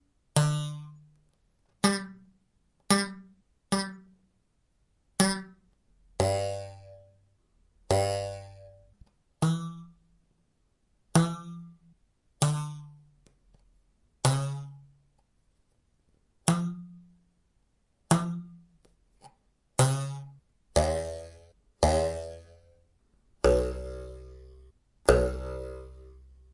描述：recording of a sawblade.
声道立体声